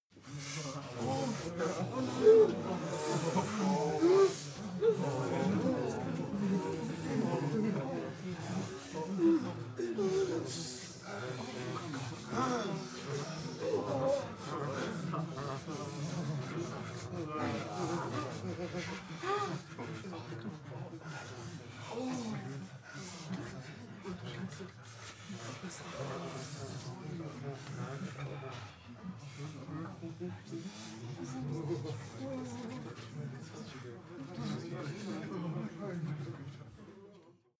Royalty free sounds: Body sounds